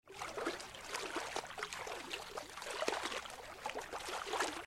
Шум бассейна: Проводим рукой или ногой по воде